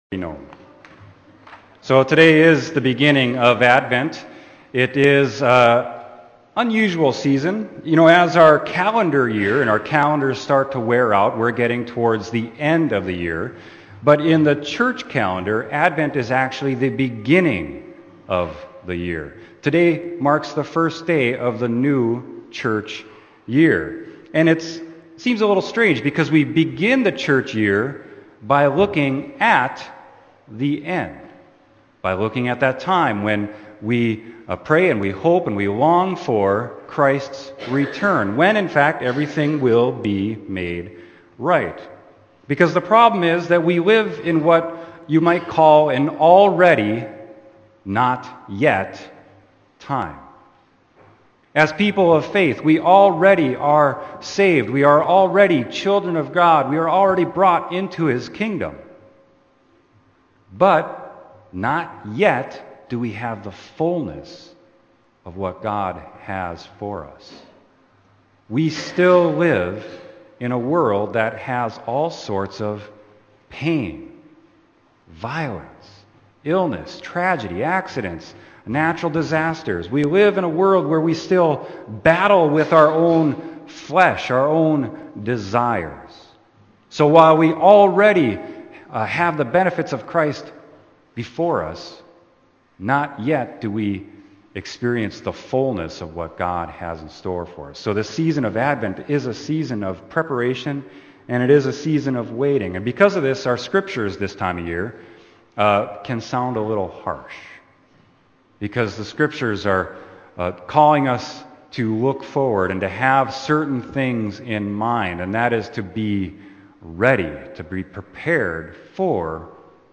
Sermon: Romans 13:11-14